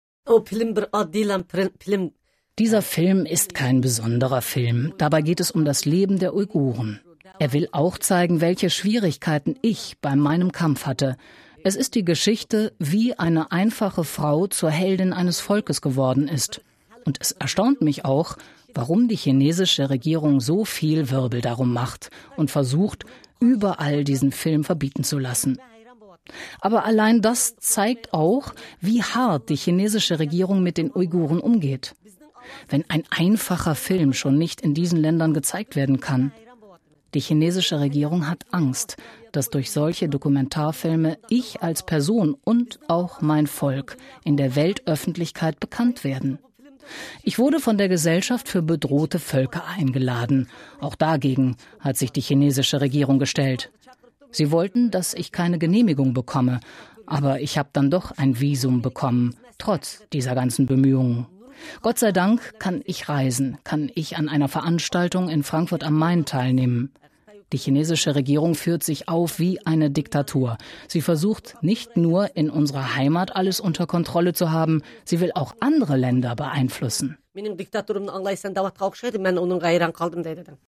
CD, Sprecherin, Voice-over